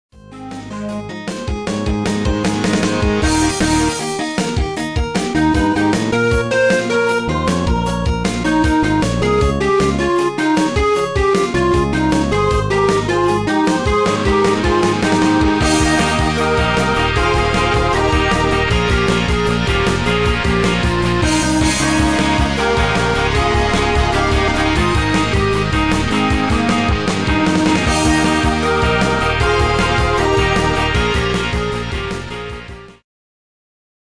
Category: Pop Tag: Pop Rock